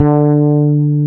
Index of /90_sSampleCDs/Roland L-CD701/BS _E.Bass 5/BS _Dark Basses